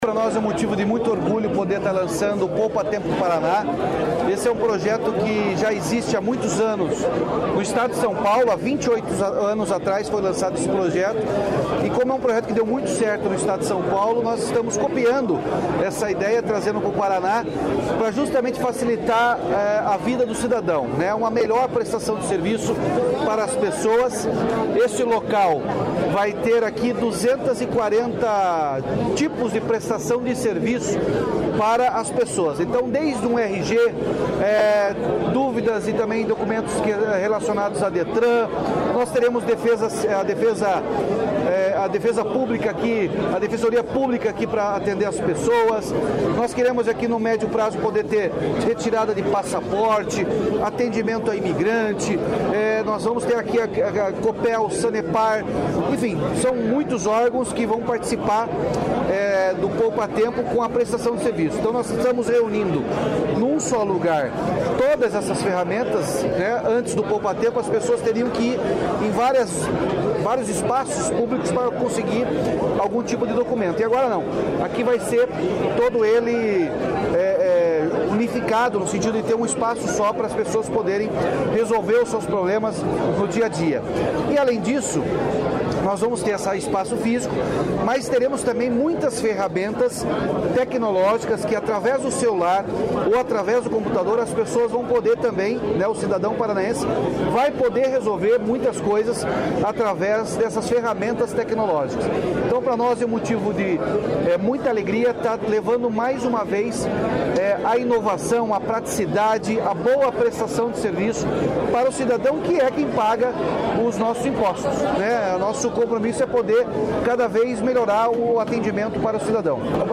Sonora do governador Ratinho Junior sobre a 1ª unidade do Poupatempo